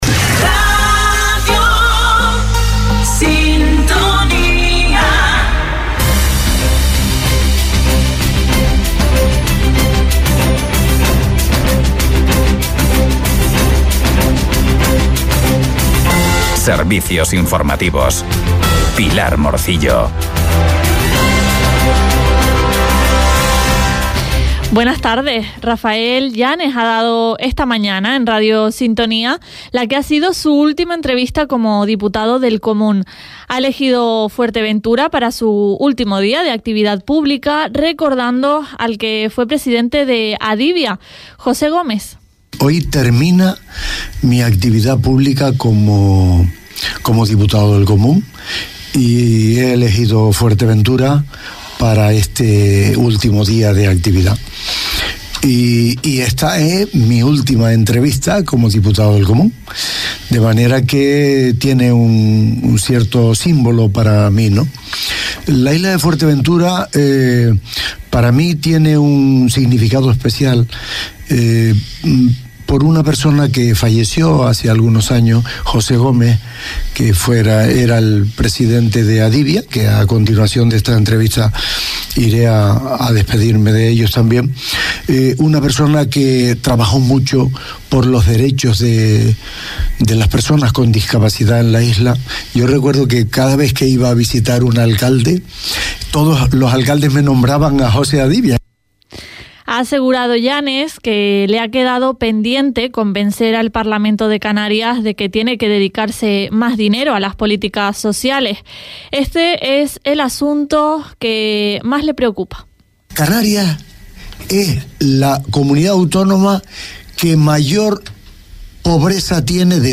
Informativos en Radio Sintonía - 06.05.24 - Radio Sintonía